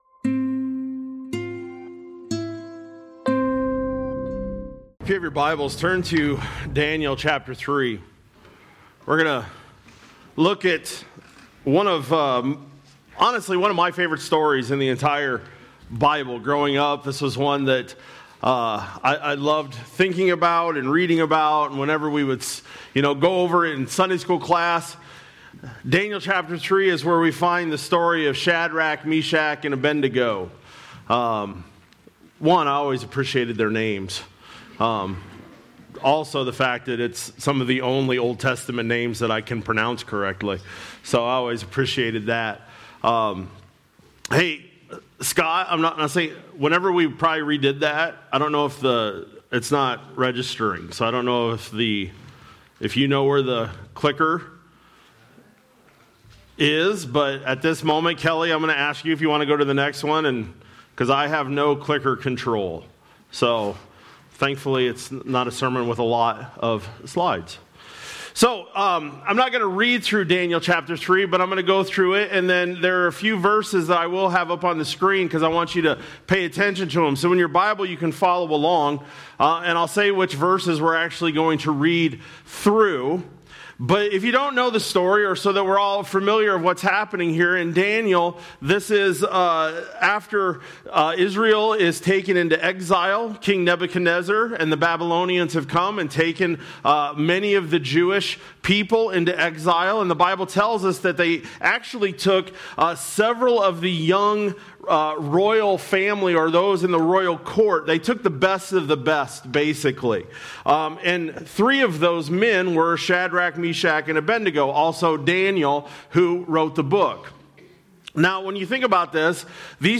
Nov-23-25-Sermon-Audio.mp3